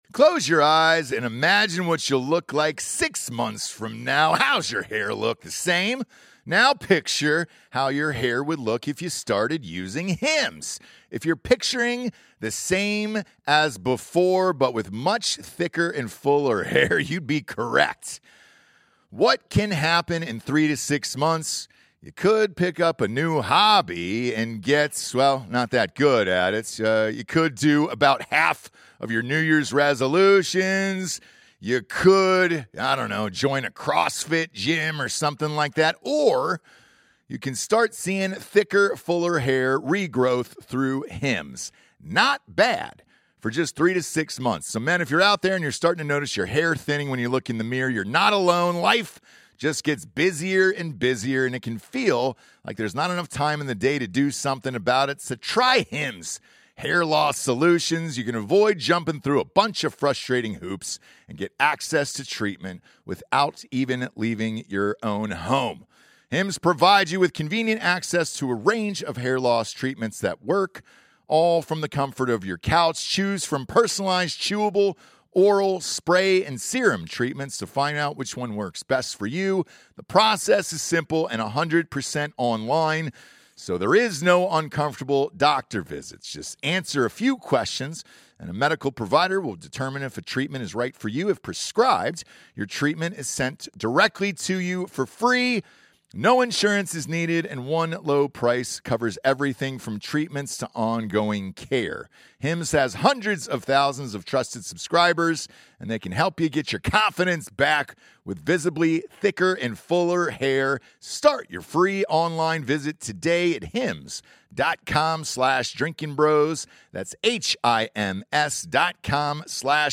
Politics, Comedy, News, Sports